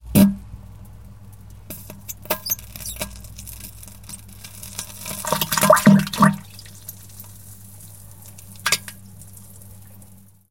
Звук, когда дерьмо вылезает из жопы человека